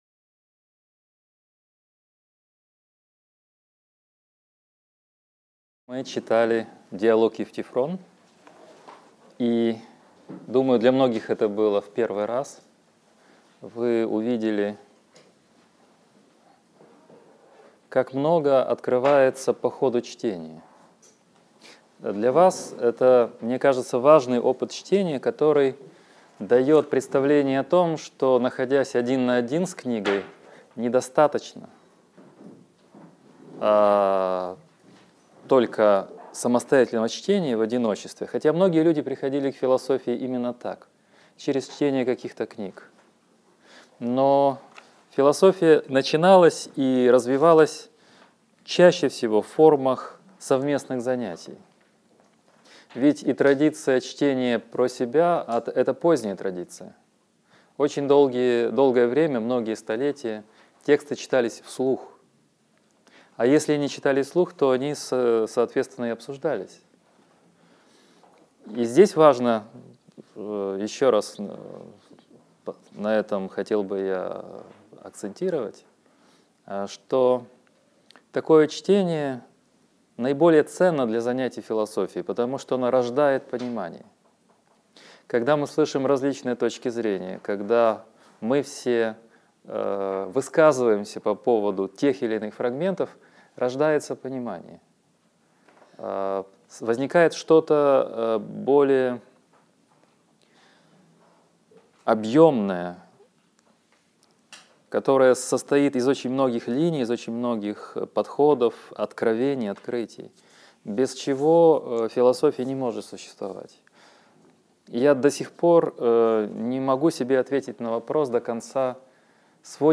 Аудиокнига Лекция 11. Диалог Платона «Федон». Платонизм в простом изложении | Библиотека аудиокниг